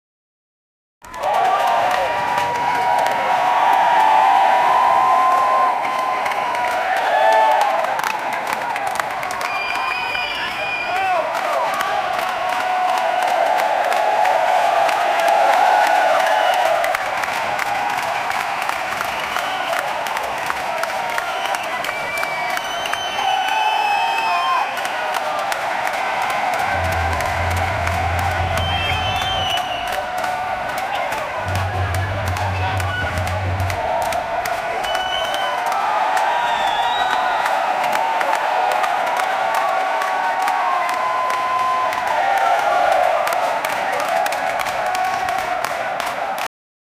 running-race-sound-and-ch-kdva6wus.wav